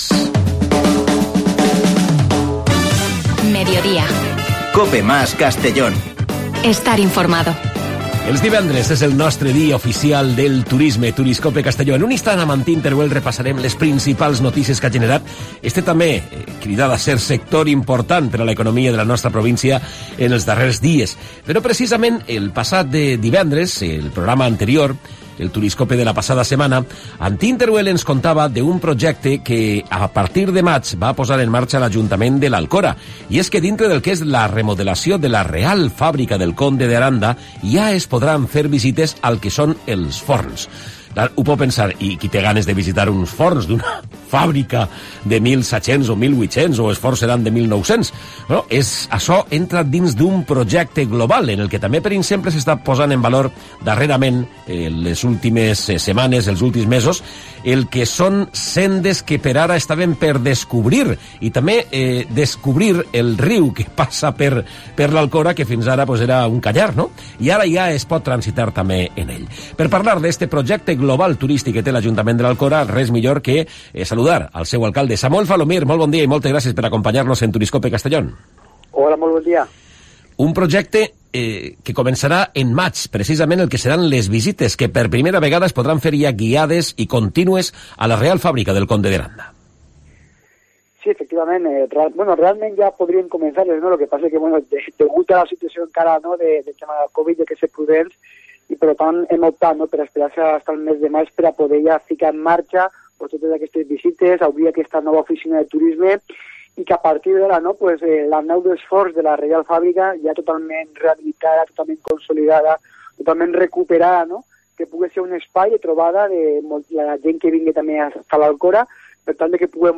Hoy con el alcalde de L'Alcora Samuel Falomir y la actualidad del sector.